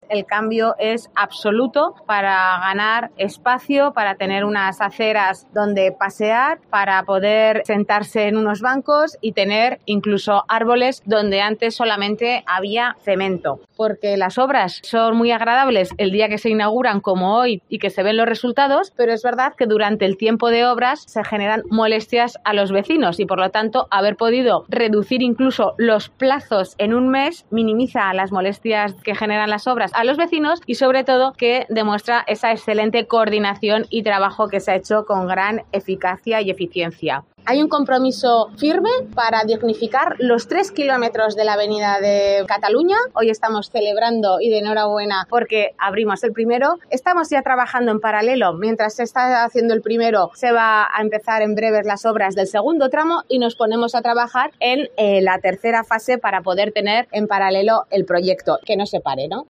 Así es la nueva Avenida de Cataluña. La alcaldesa, Natalia Chueca, explica los detalles